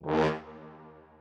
strings4_17.ogg